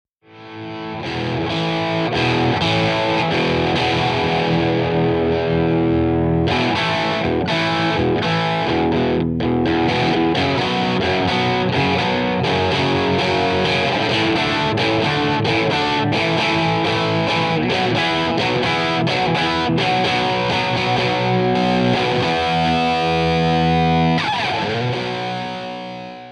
par contre sur des grattes ça peut donner un coté sympa :